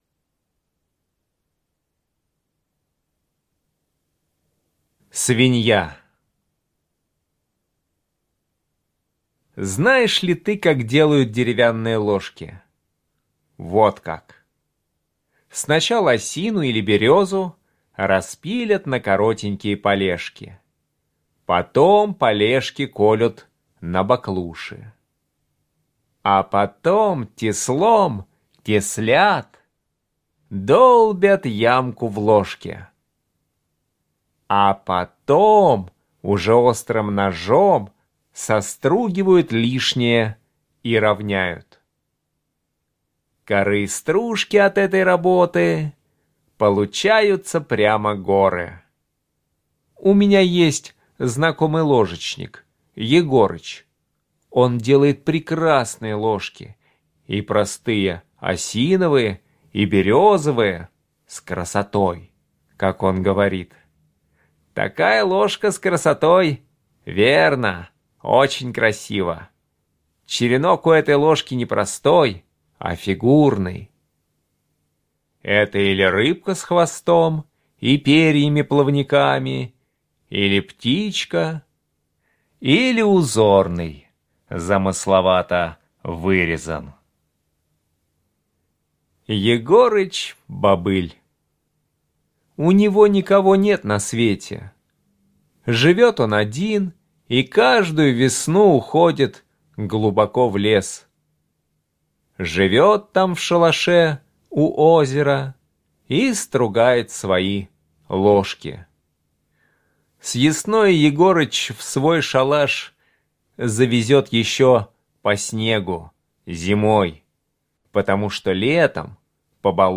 Слушайте Свинья - аудио рассказ Чарушина Е.И. Рассказ про Егорыча, который был одинокий и каждую весну уходил жить глубоко в лес.